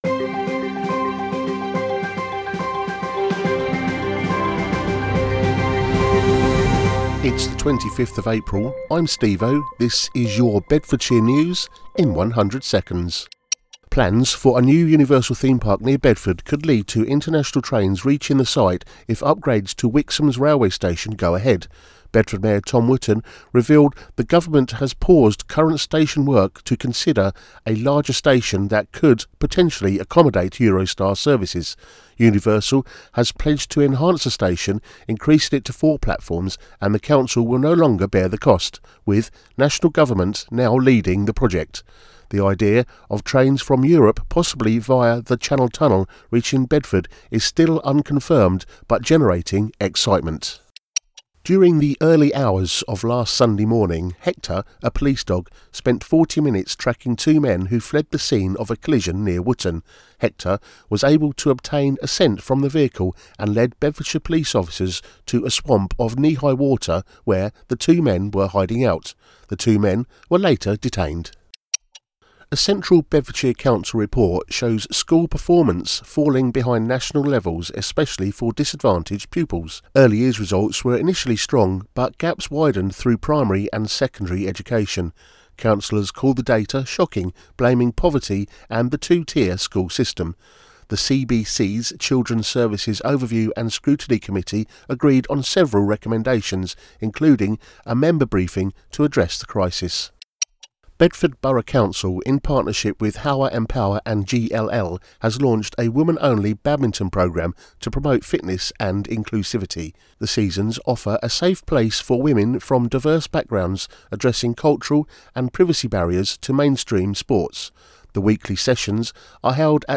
A free audio news roundup for Bedford and the greater Bedfordshire area, every weekday.